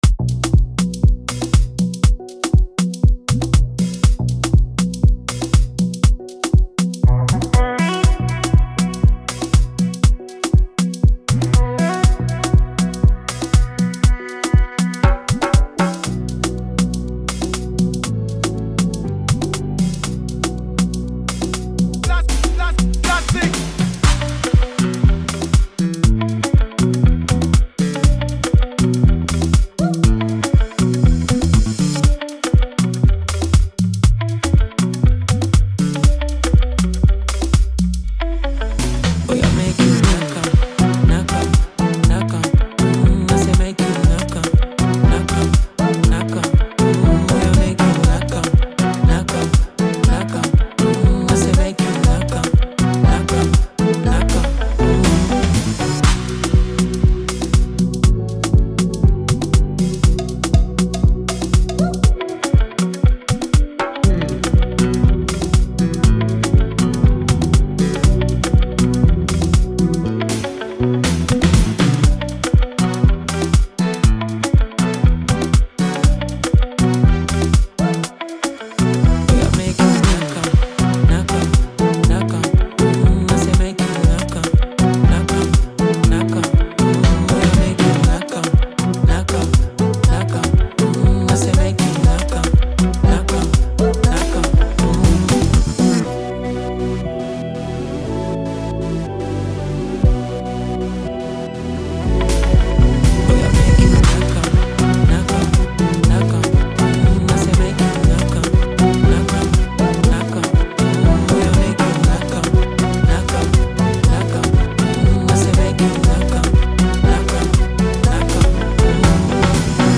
Beat Type: Afrobeat